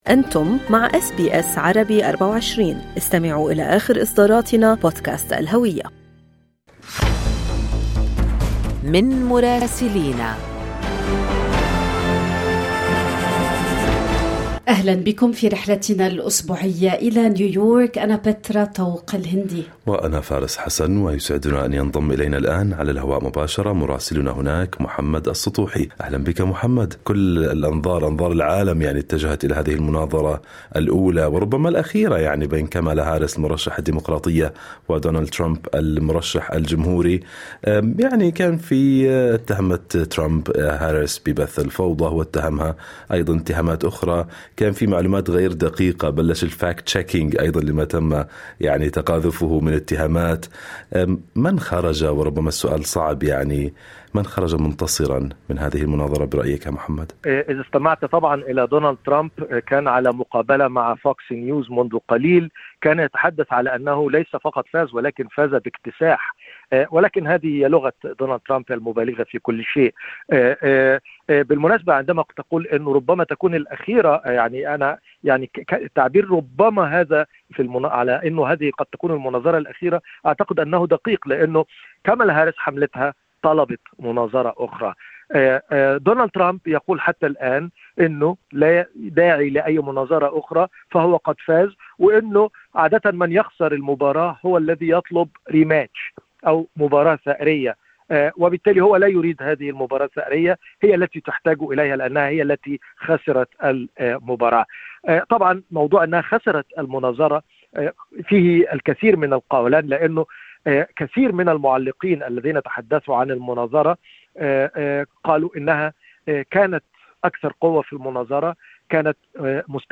من مراسلينا: أخبار الولايات المتحدة الأمريكية في أسبوع 12/9/2024